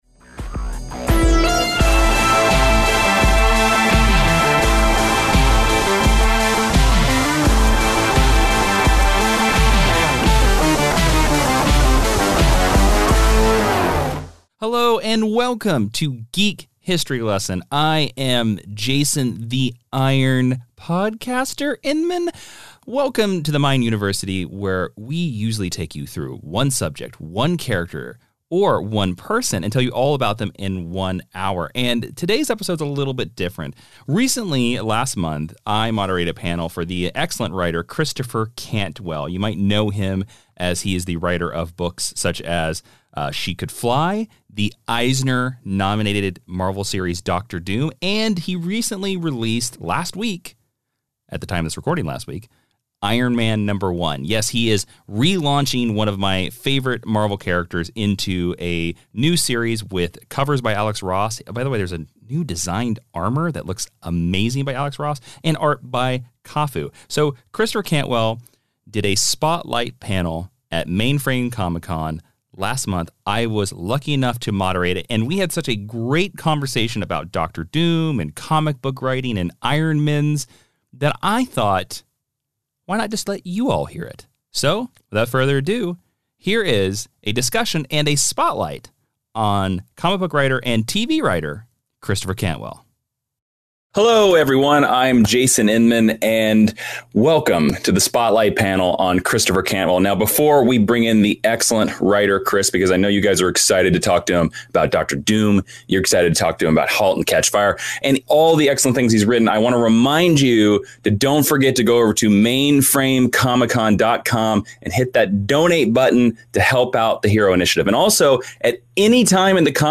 (NOTE: This panel was recorded LIVE as part of MainFrame Comic Con.)